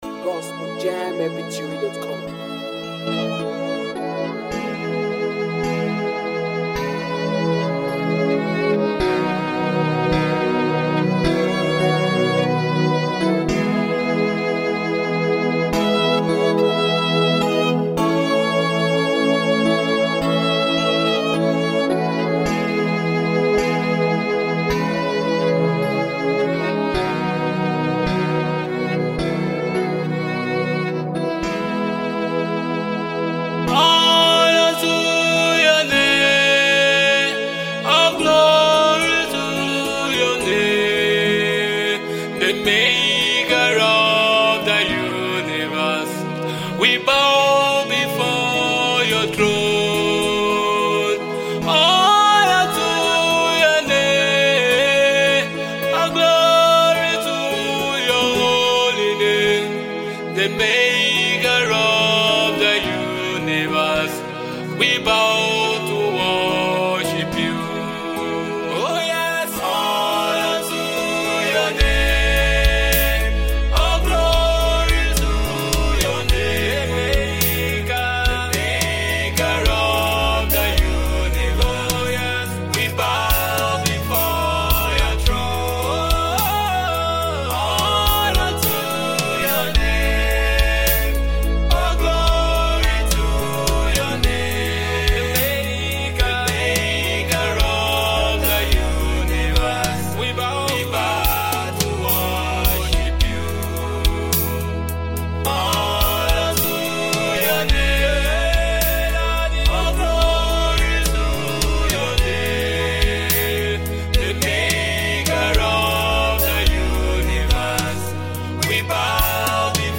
Nigeria Gospel single